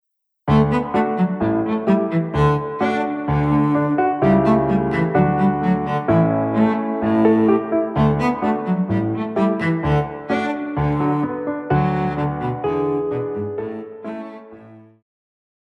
Classical
Cello
Piano
Instrumental
Solo with accompaniment
But this version is specially made for cello and piano.